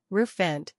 roof - vent